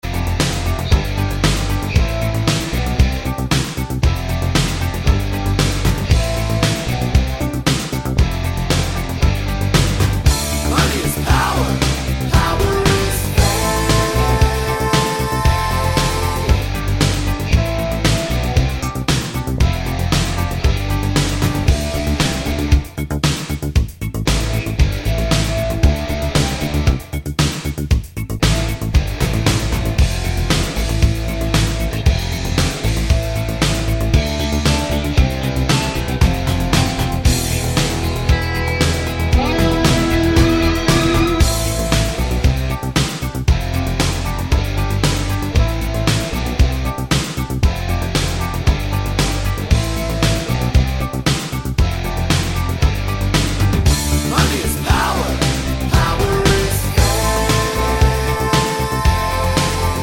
Duet Version Rock 4:53 Buy £1.50